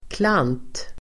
Ladda ner uttalet
klant.mp3